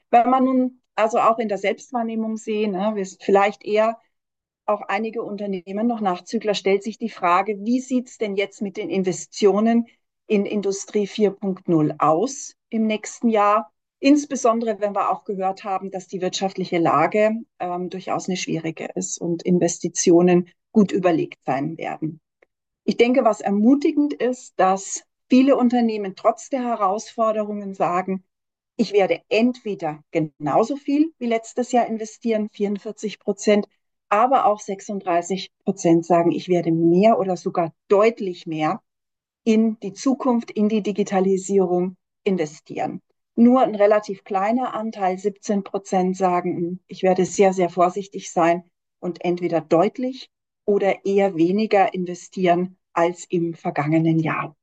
Mitschnitte der Pressekonferenz
mitschnitt-pressekonferenz-ki-iot-fabrik-im-metaverse-investitionen-der-industrie-in-digitale-technologien.mp3